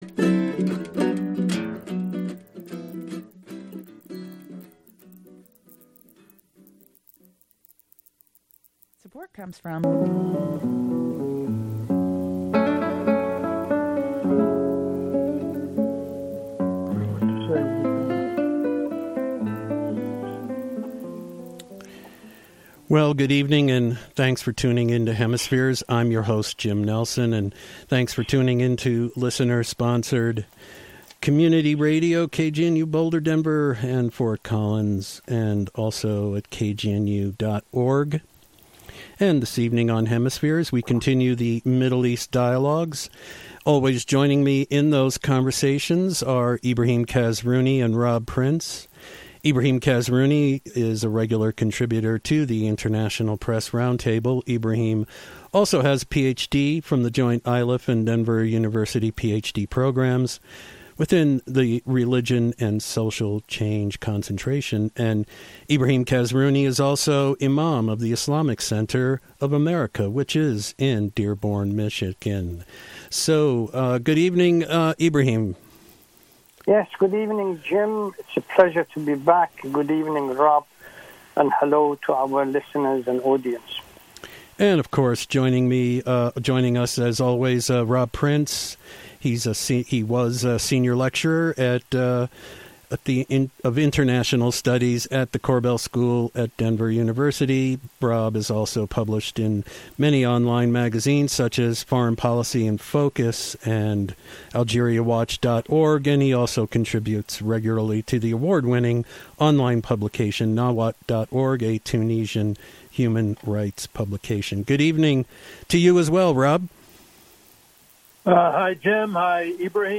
The audio tape of last night’s KGNU program.
All that and listener phone calls will be welcome.